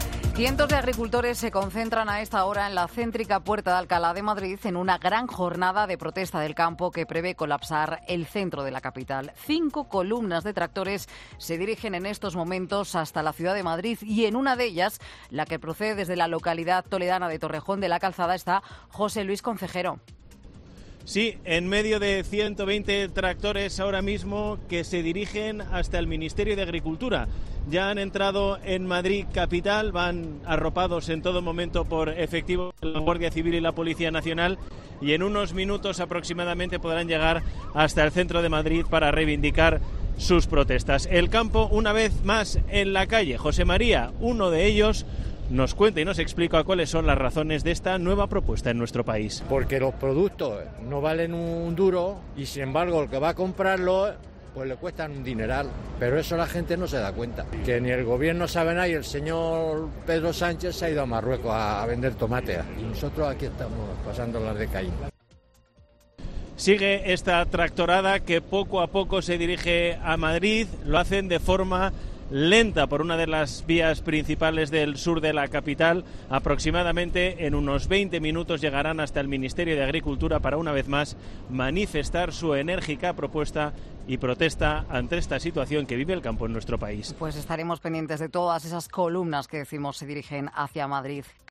testigo